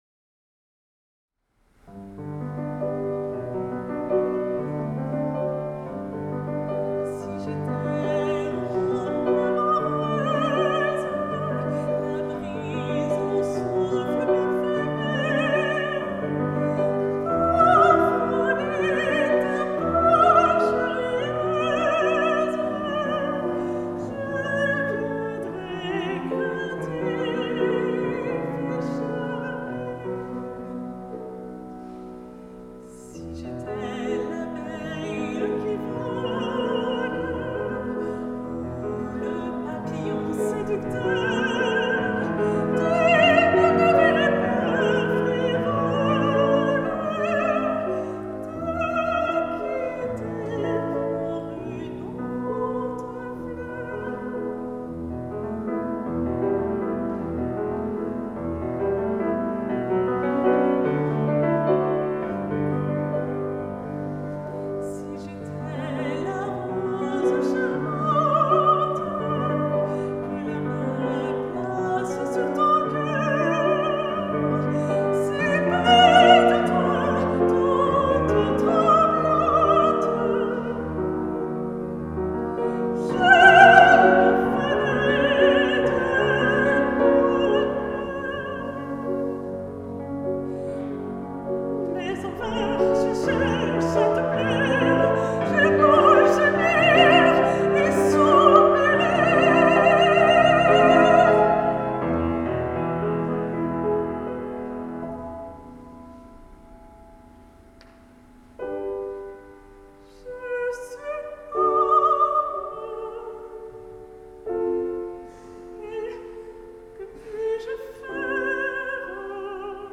Alto
Piano
Le trio d'Elles, ce sont trois femmes, deux voix et un piano qui vous proposent de beaux et émouvants moments de musique.